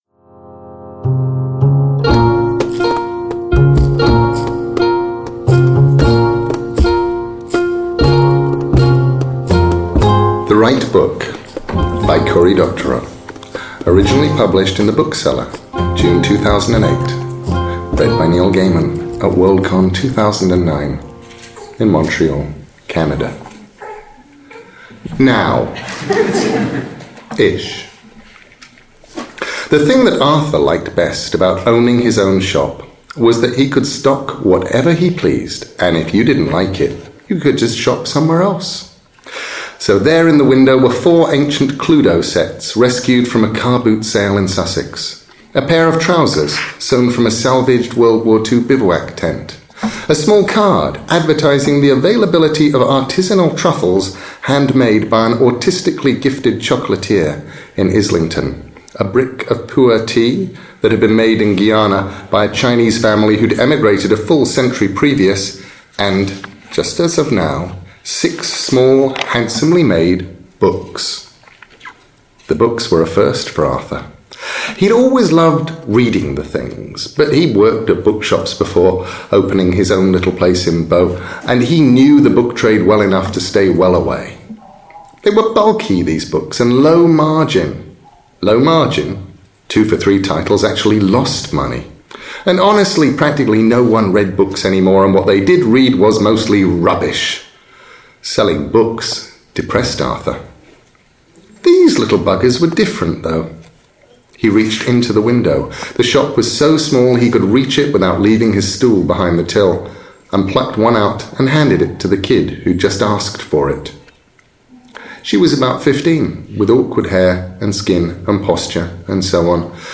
Finally, every day for the next 2 weeks, I will be publishing a free chapter of his audio book as a daily special podcast feature for Singularity 1 on 1 . Today I am posting Chapter 3 - The Right Book.